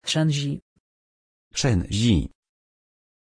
Pronunciation of Shenzi
pronunciation-shenzi-pl.mp3